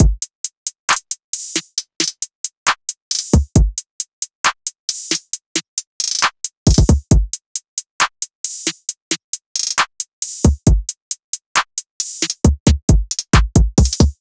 SOUTHSIDE_beat_loop_cash_full_135.wav